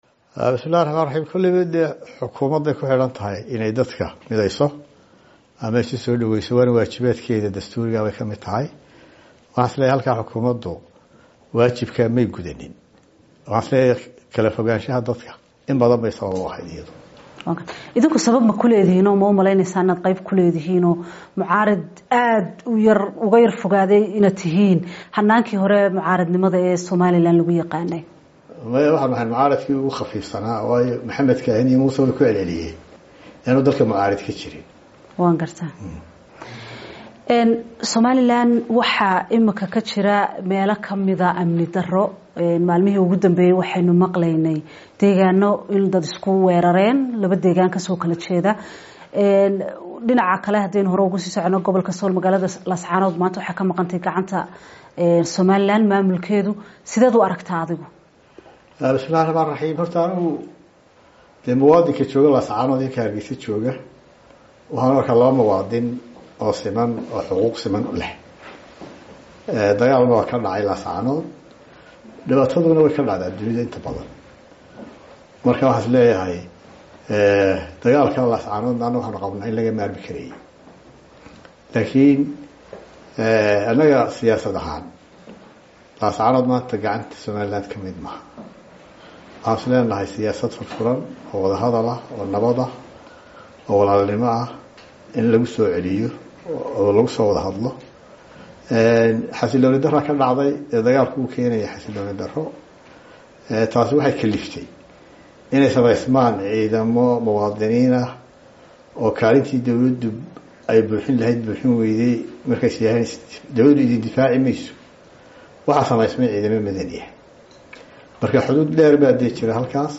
Wareysi: Musharrax Madaxweyne Cabdiraxman Cirro